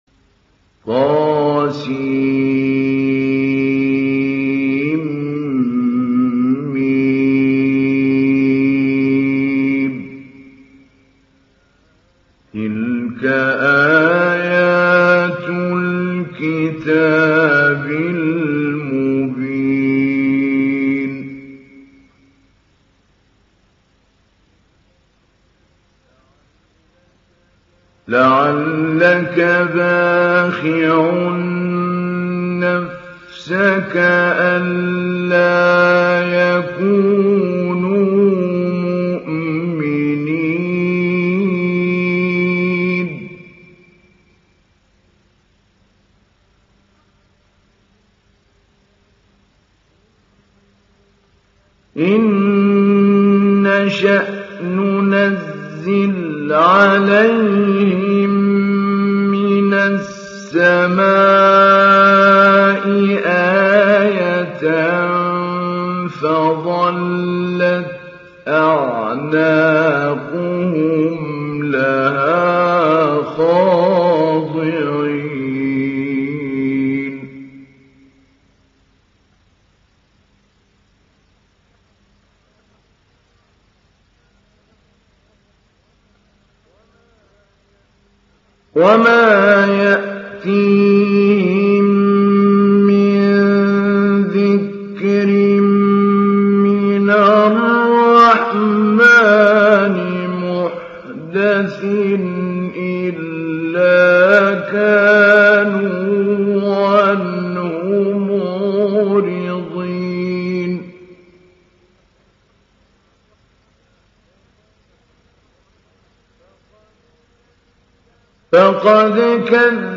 Sourate Ash Shuara Télécharger mp3 Mahmoud Ali Albanna Mujawwad Riwayat Hafs an Assim, Téléchargez le Coran et écoutez les liens directs complets mp3
Télécharger Sourate Ash Shuara Mahmoud Ali Albanna Mujawwad